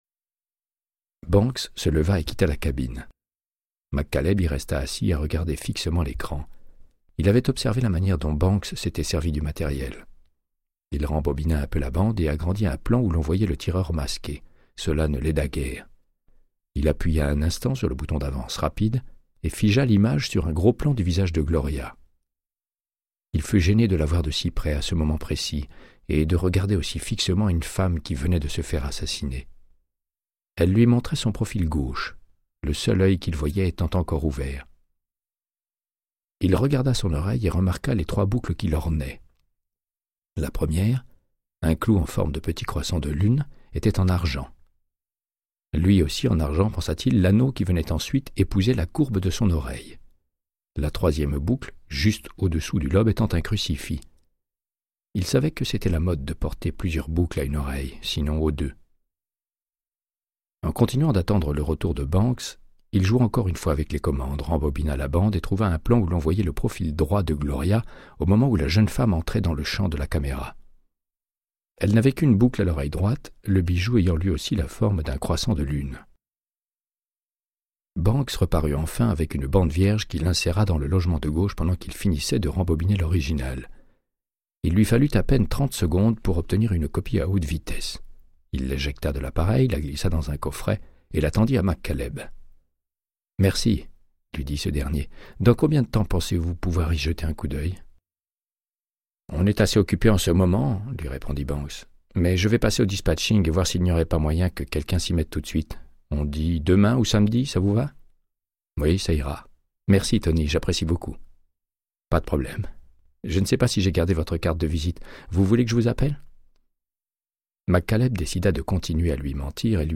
Audiobook = Créance de sang, de Michael Connellly - 58